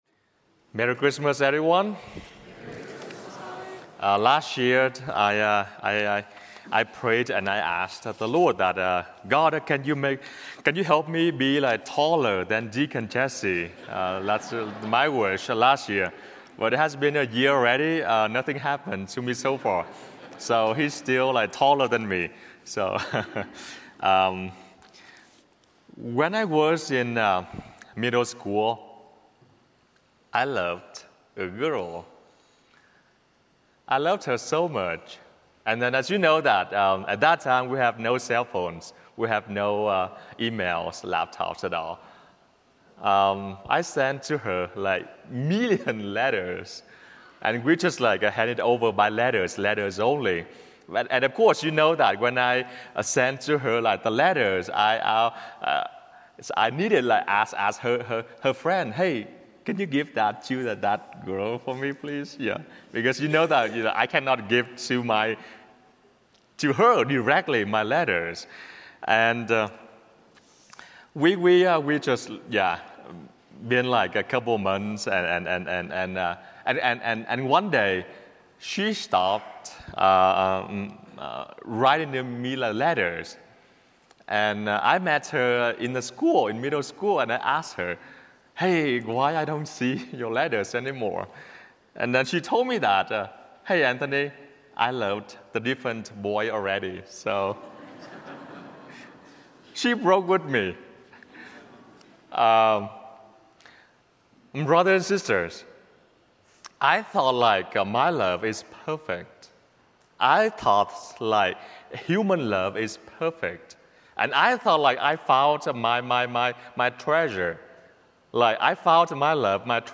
08 Jan Christmas Eve Homily